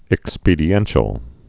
(ĭk-spēdē-ĕnshəl)